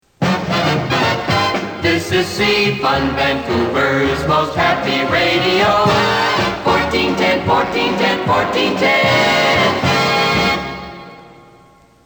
JINGLES FROM EARLY 'FUNLAND' RADIO